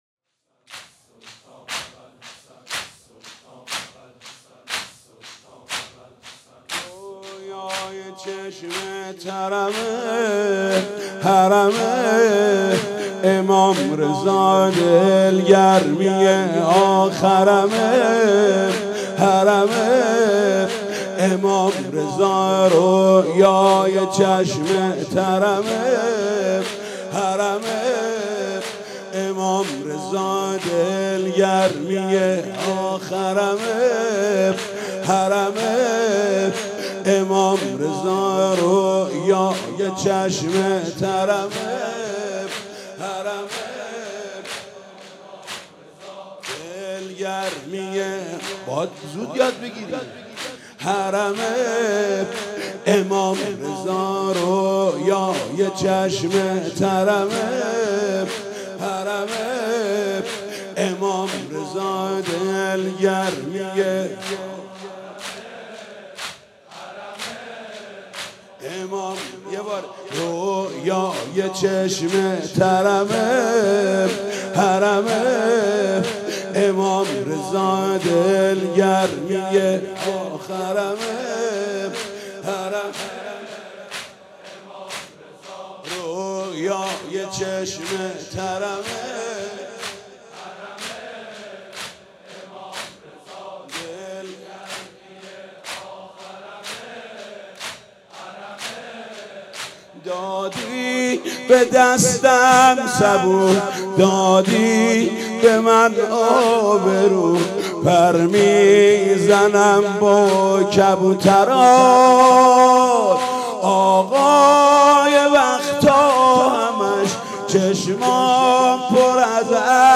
دانلود نوحه شهادت امام رضا (ع) از محمود کریمی